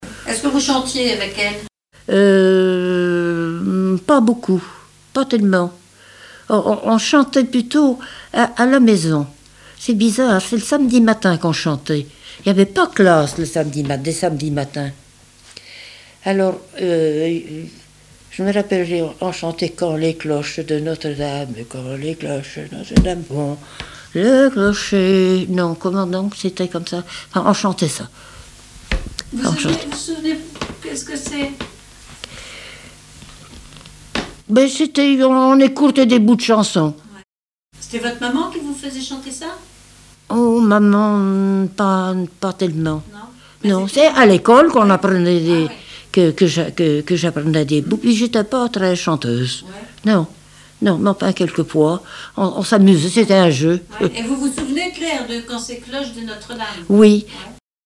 Chansons et témoignages
Catégorie Témoignage